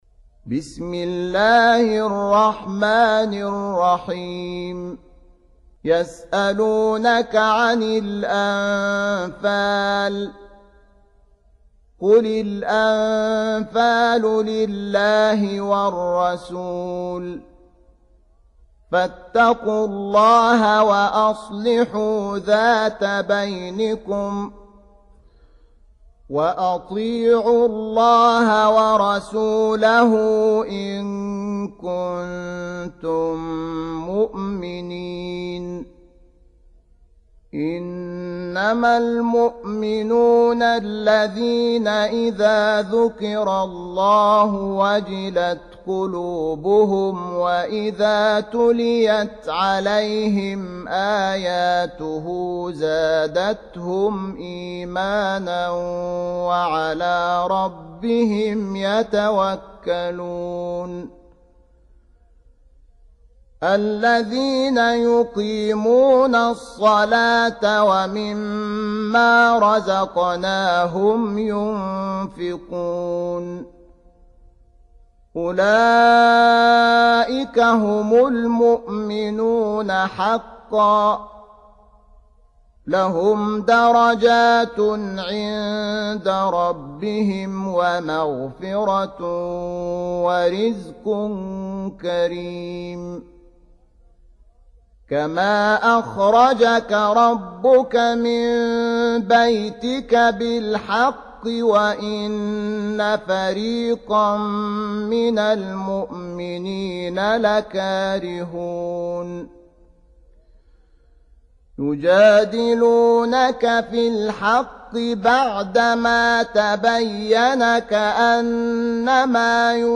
8. Surah Al-Anf�l سورة الأنفال Audio Quran Tarteel Recitation
Surah Repeating تكرار السورة Download Surah حمّل السورة Reciting Murattalah Audio for 8.